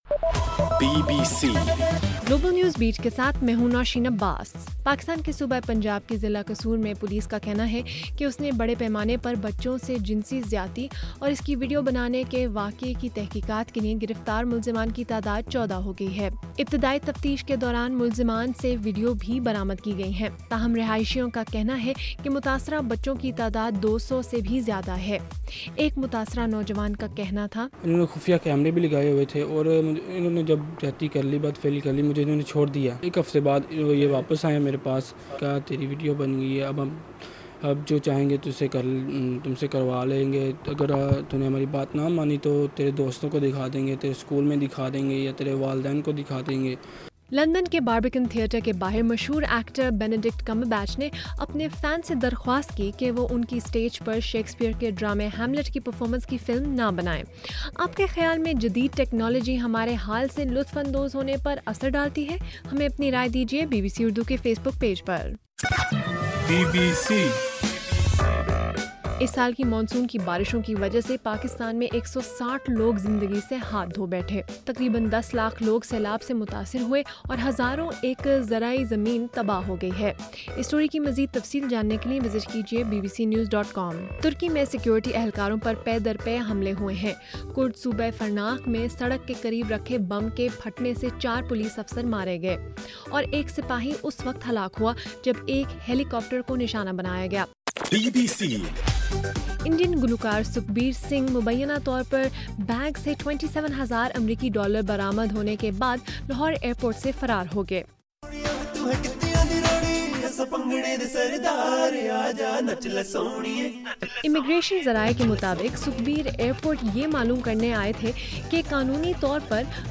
اگست 10: رات 8 بجے کا گلوبل نیوز بیٹ بُلیٹن